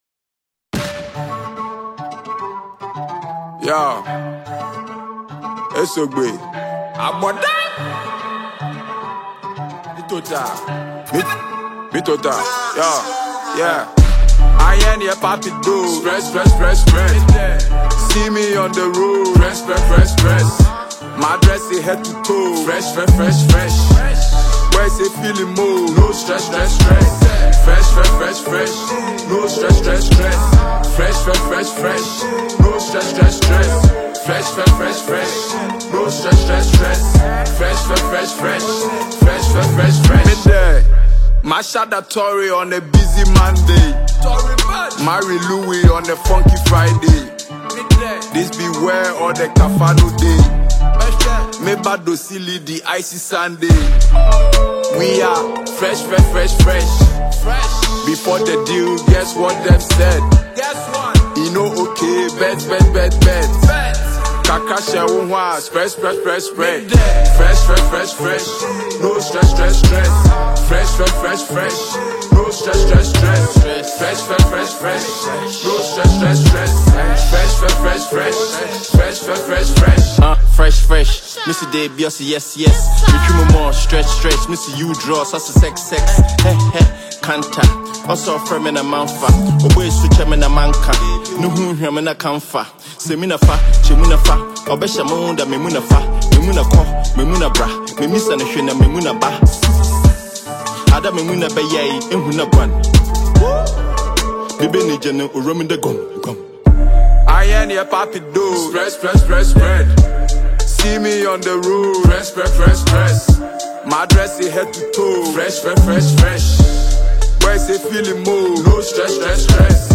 Ghanaian rap